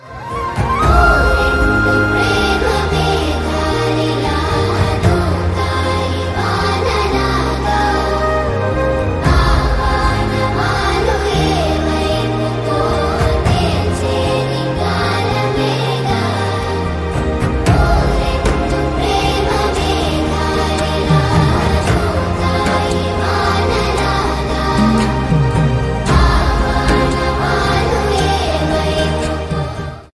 a gentle song with a bright melody and simple lyrics